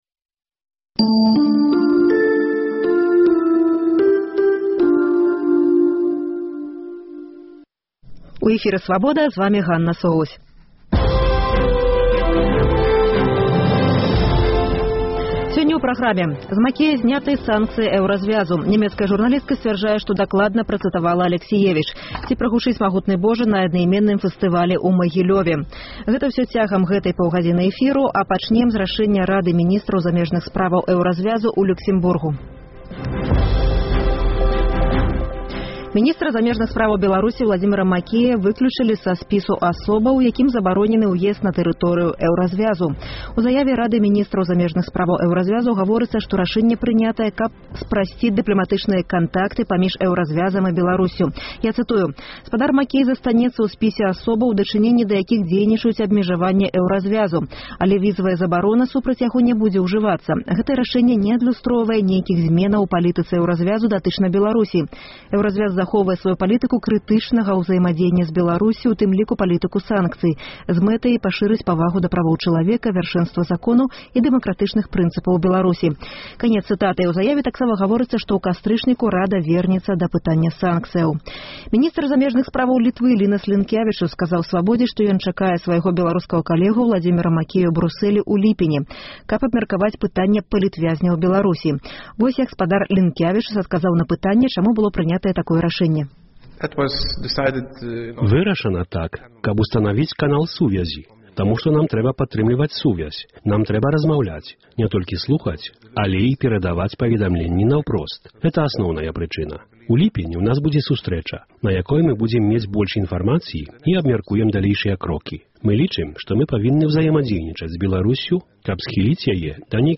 Меркаваньні беларускіх і эўрапейскіх палітыкаў.